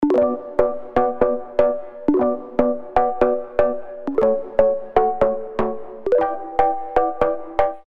• Качество: 320, Stereo
мелодичные
без слов
звонкие
Простая мелодия под оповещения